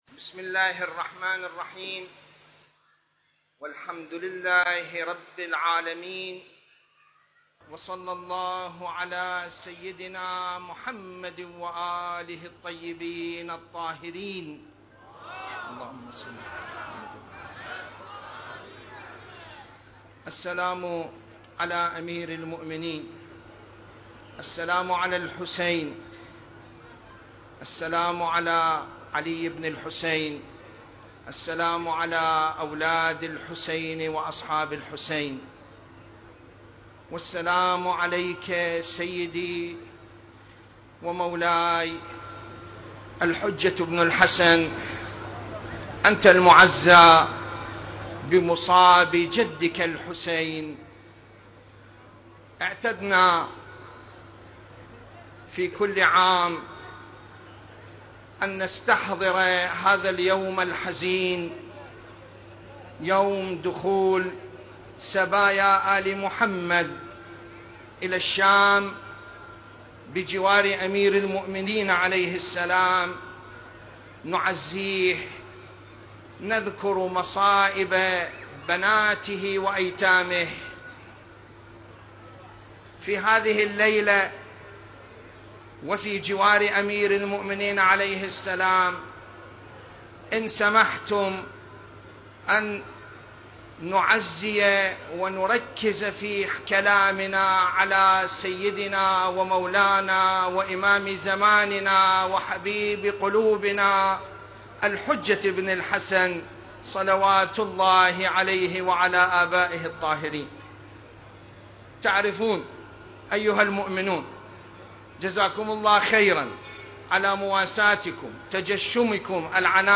المكان: العتبة العلوية المقدسة التاريخ: 2022